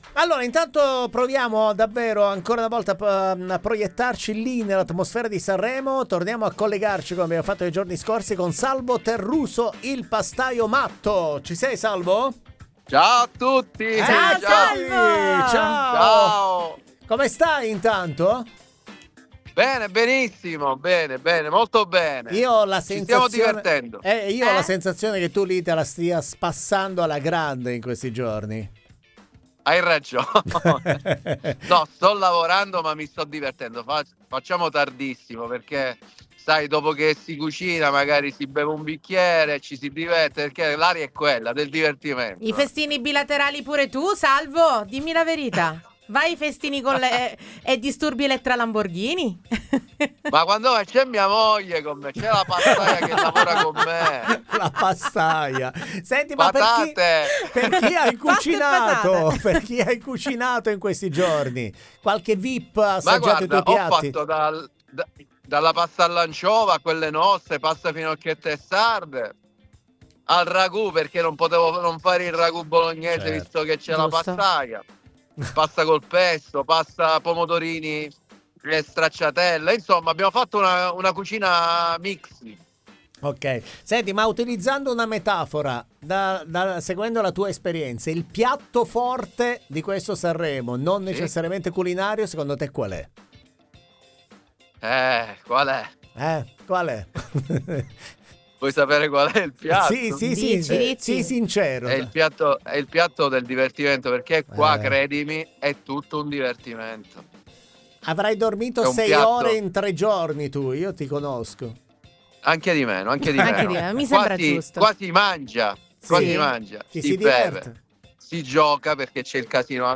IN COLLEGAMENTO DA SANREMO